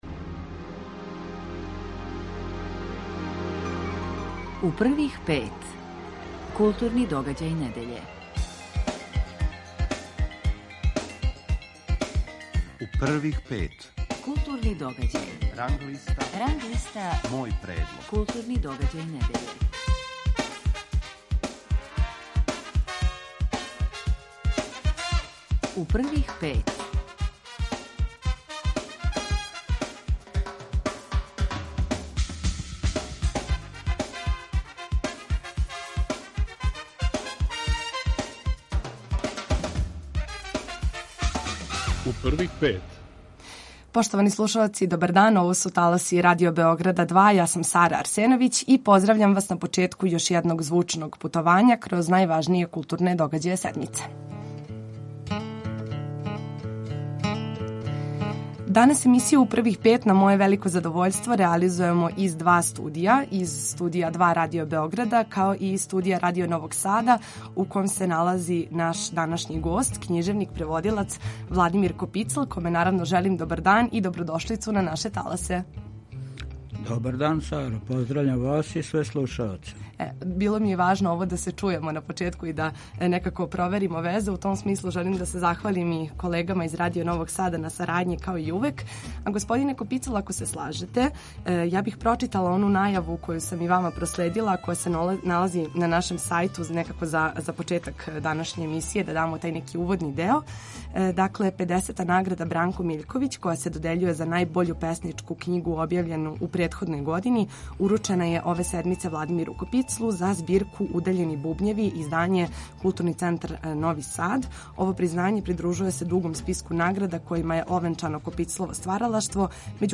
Гост емисије је Владимир Kопицл.